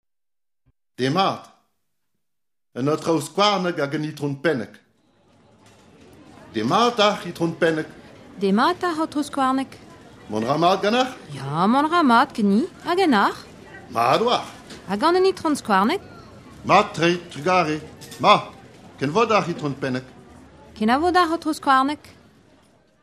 Divizioù